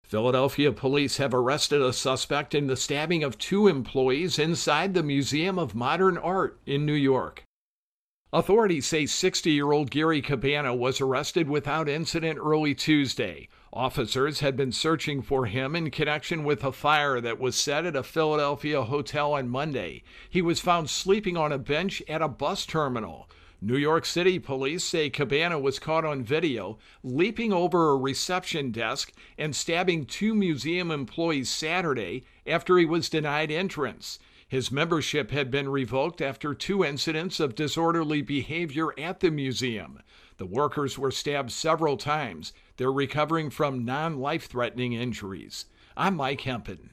Intro and voicer for New York-Museum Stabbing